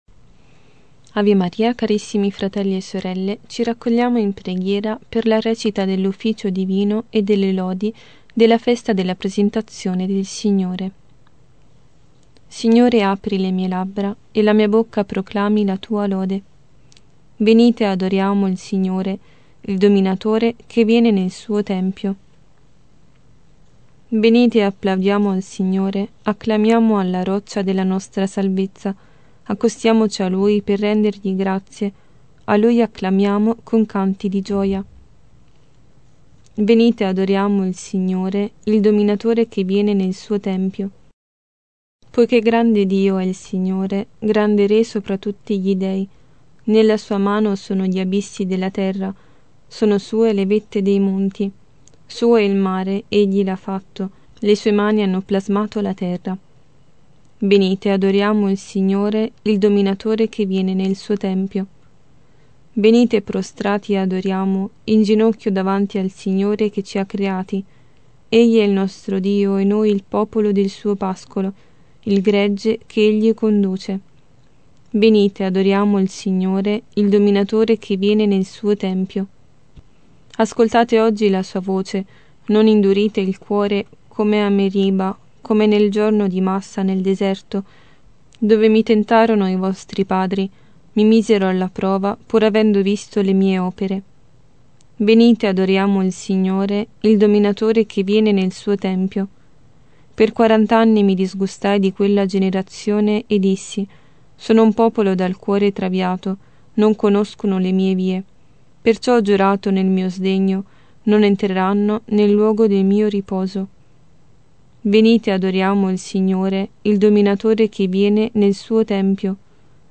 Ufficio e Lodi Mattutine – Festa della presentazione del Signore